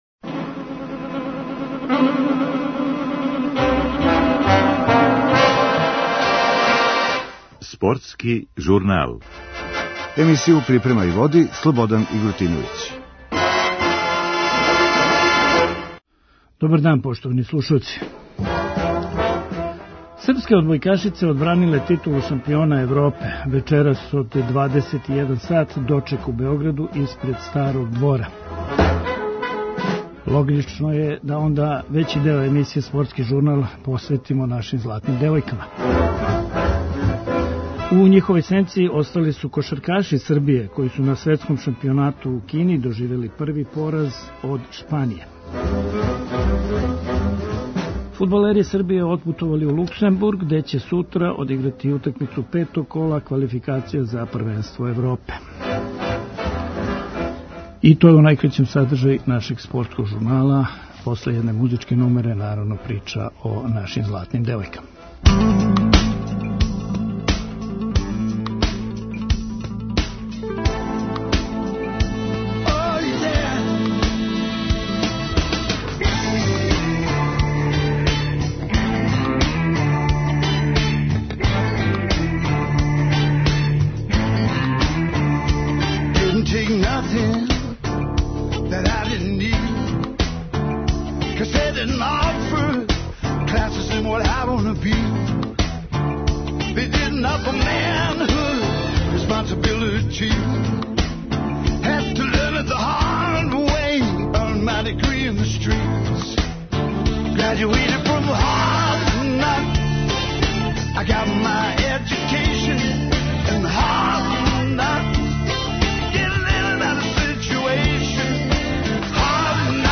Наш национални тим је јутрос отпутовао са аеродрома Никола Тесла, а изјаве фудбалера Србије чућете у нашој емисији.